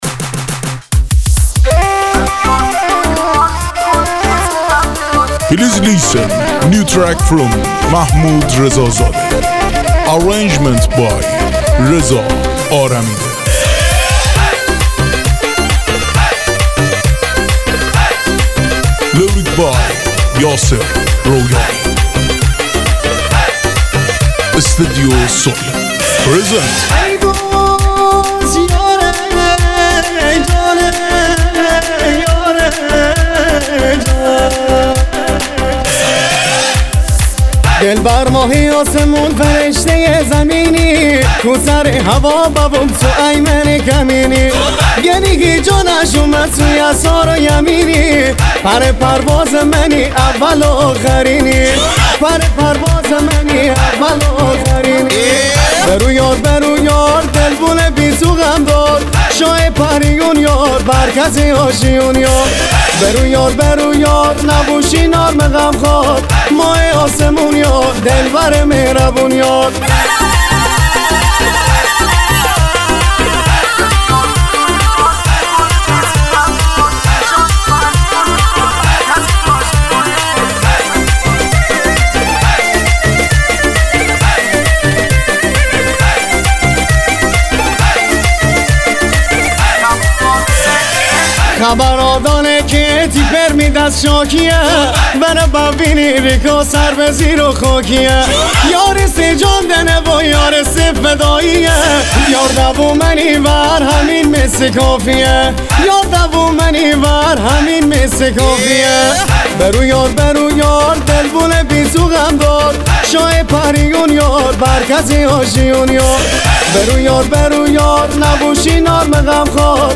آهنگ مازندرانی